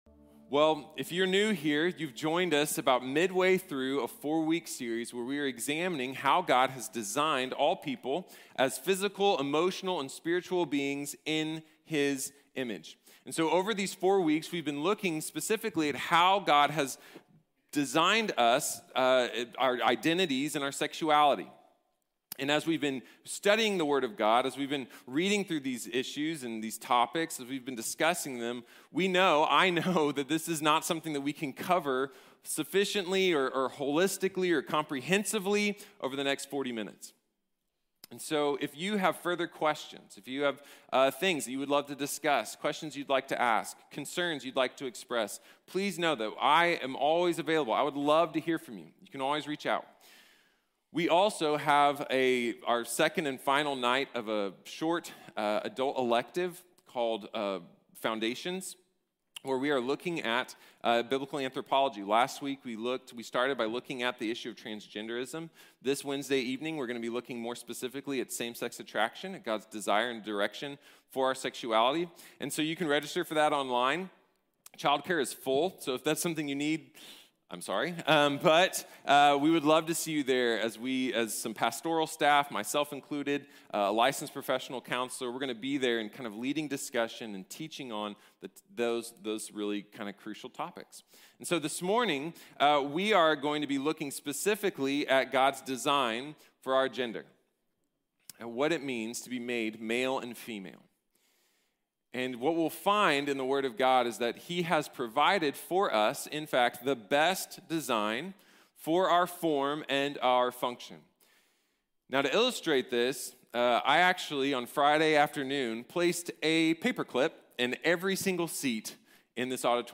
Gender & Identity | Sermon | Grace Bible Church